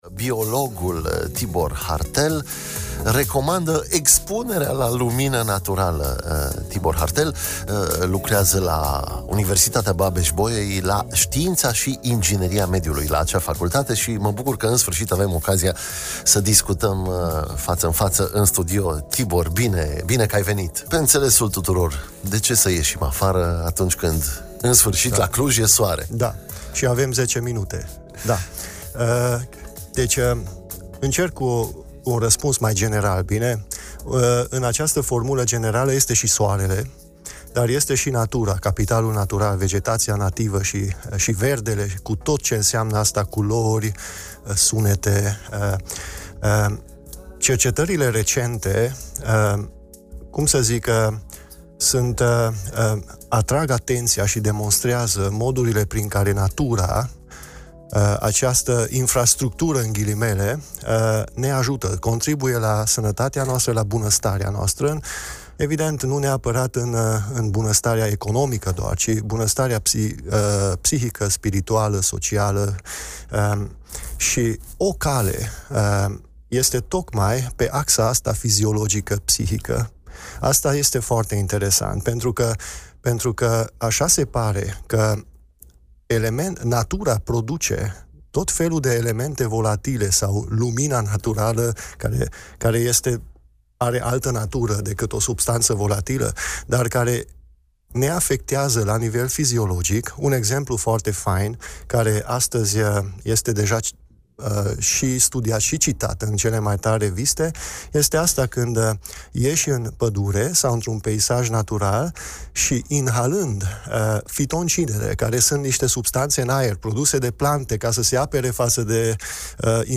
Ascultați discuția despre beneficiile demonstrate științific pe care natura le are asupra oamenilor